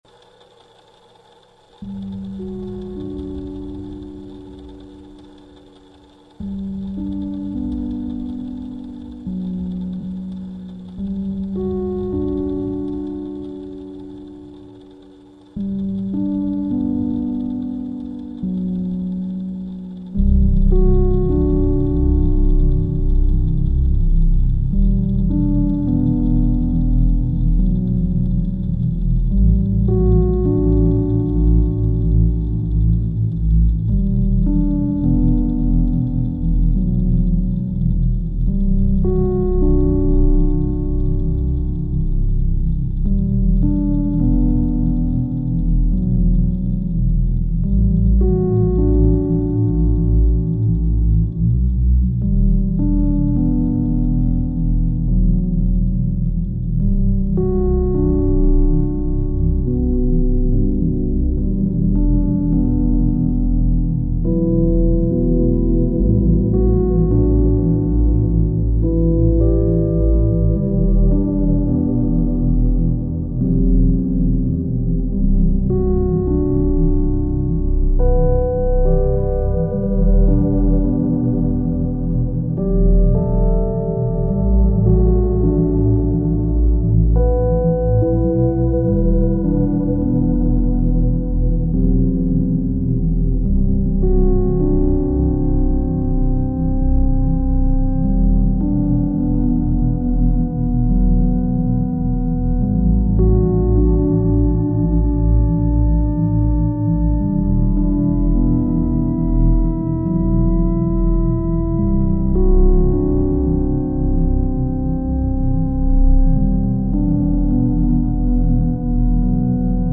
slowed down version